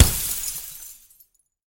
breakglass.ogg.mp3